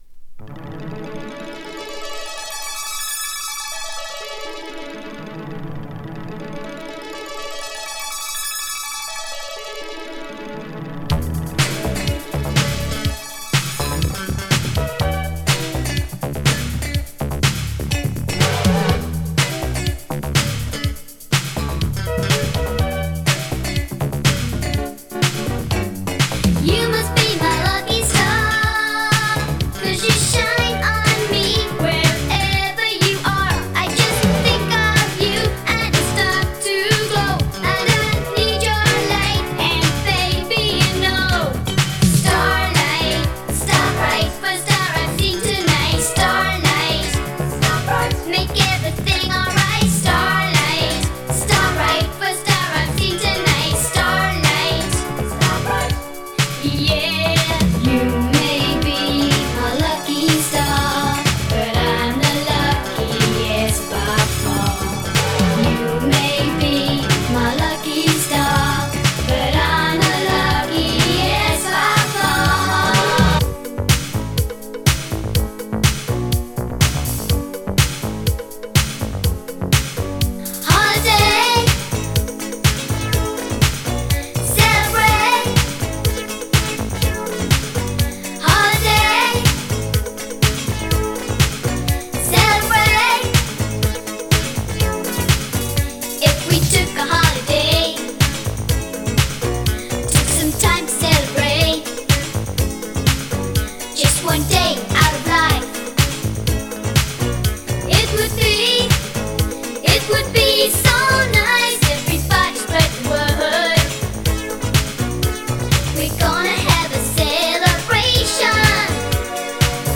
Anyway, we're back to the nice clean and crisp vinyl again!